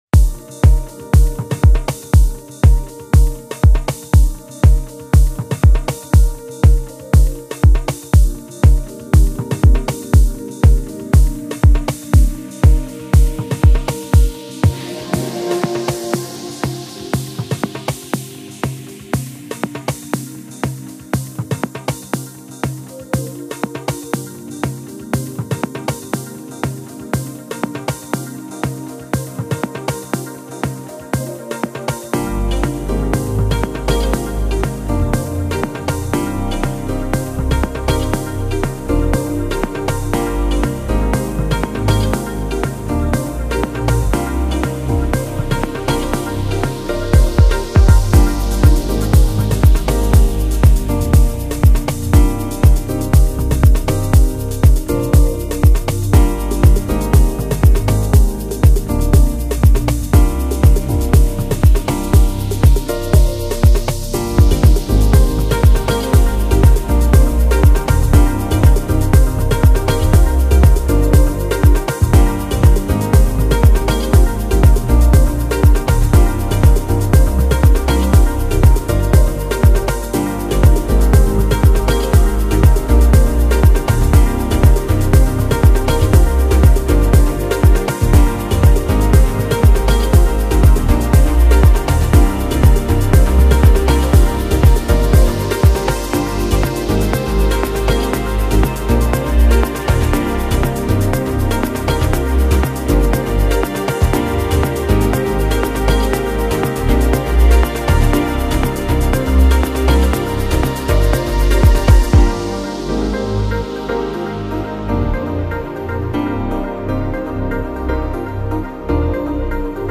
he went solo in this piece